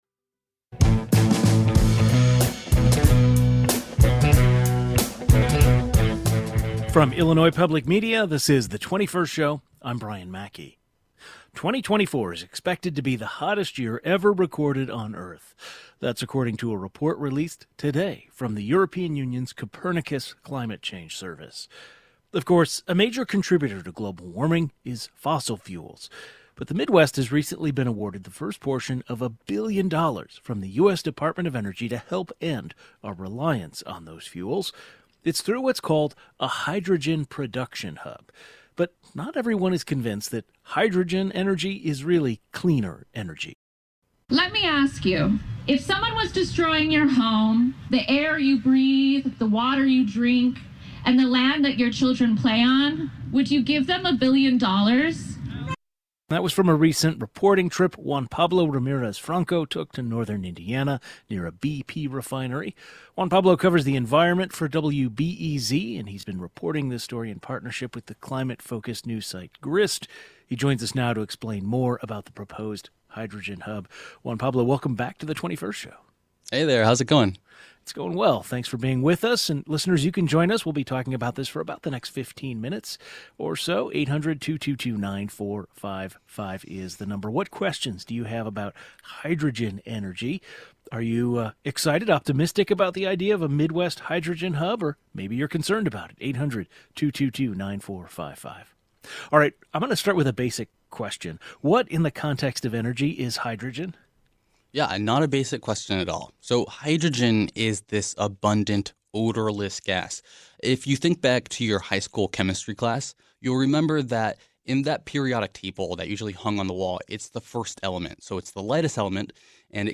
A journalist covering this story in partnership with the climate-focused news site Grist j oins the 21st to explain more about the proposed hydrogen hub.